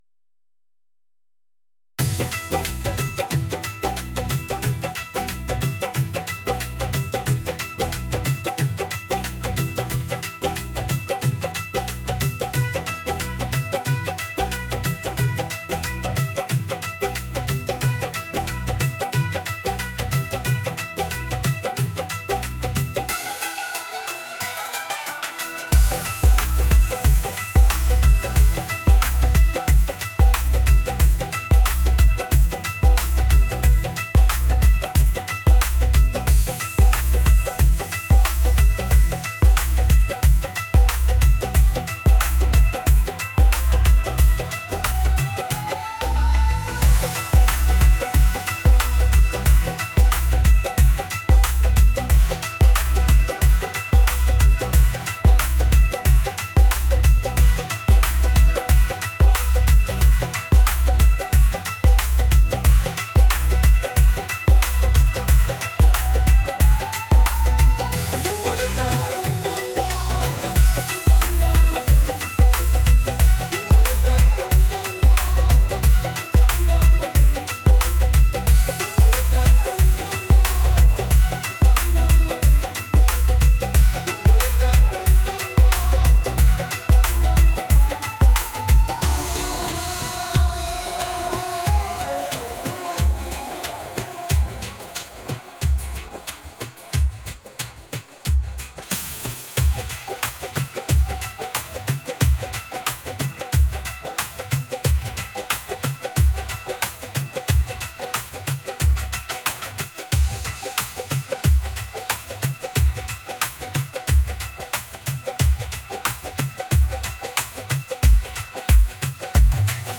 energetic | pop